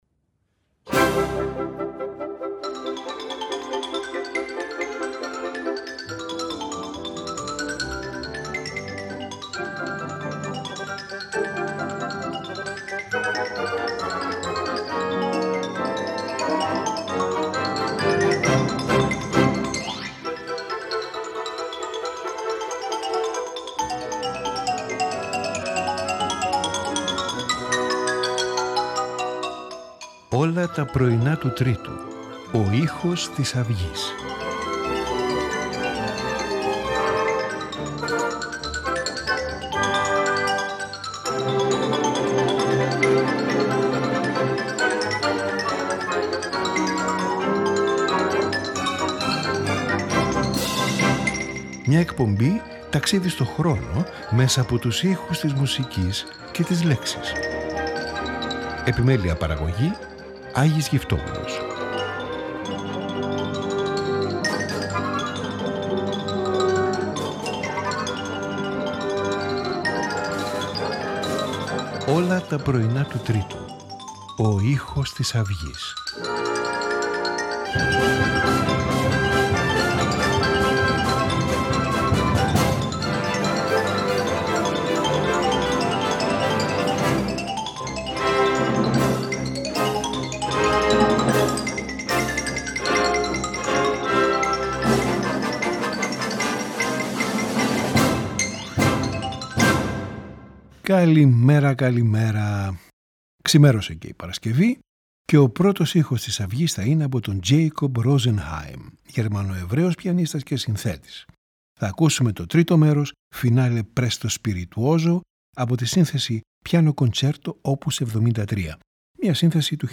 Piano Concerto
Violin Concerto
Quintet for Clarinet and Strings in A major